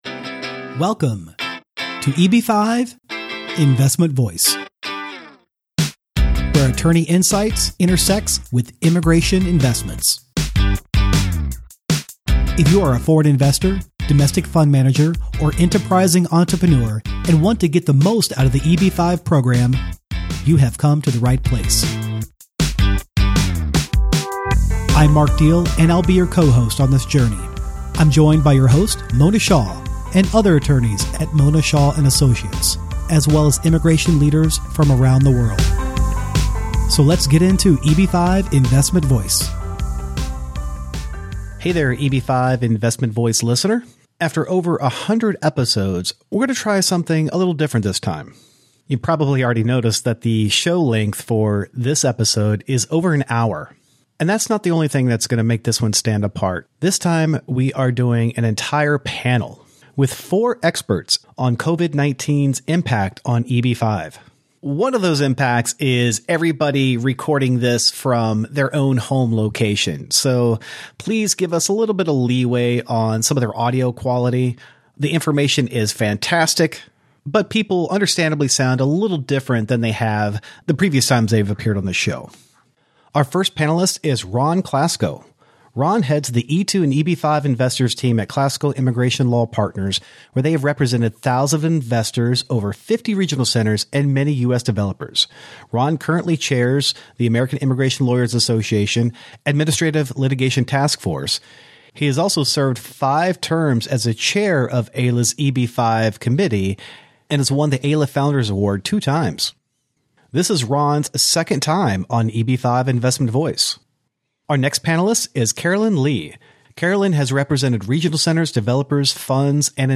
Panel Discussion: COVID-19’s Impact on EB-5 - Global Investment Voice